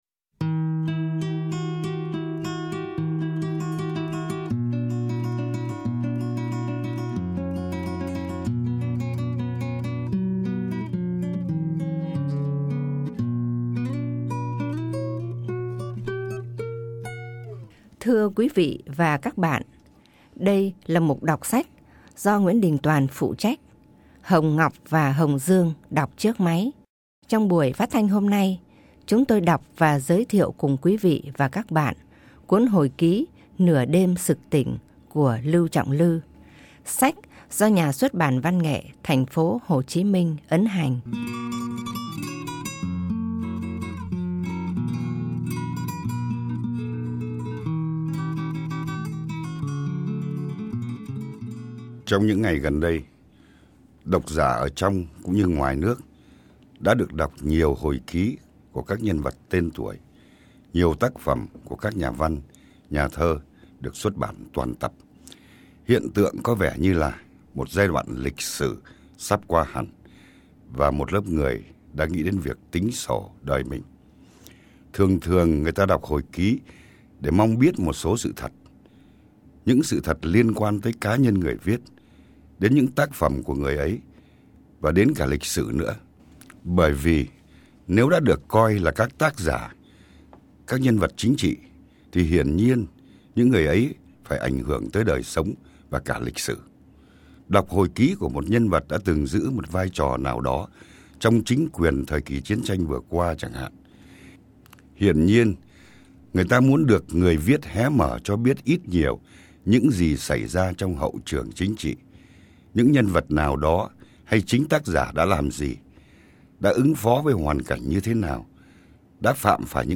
Biên sọan: Nguyễn Đình Tòan